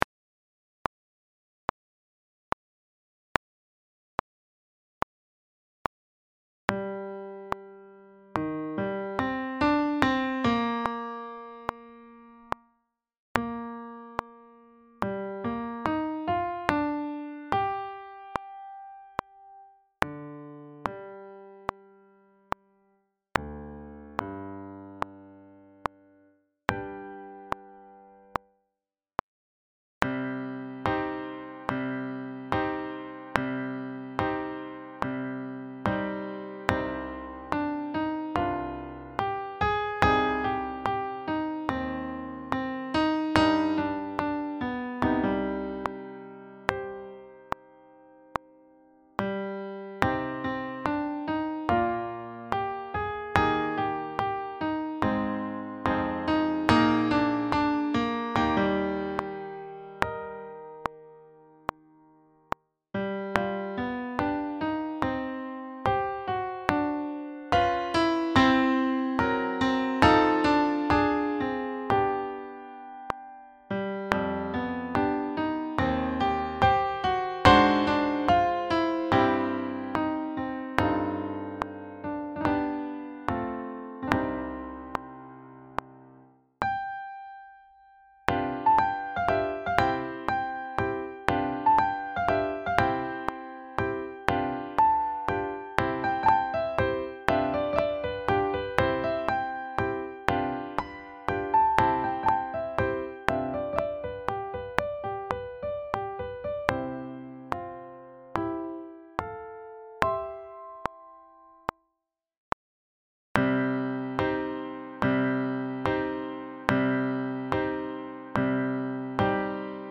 Sax Dectets
The song alternates between a deeply emotional theme and somewhat optimistic interlude, each with a distinct character. Here we place the two themes on tenor and soprano saxophones respectively, with the two joining forces for the second verse.
Backing track
172-4-solvejgssong-backing-track.mp3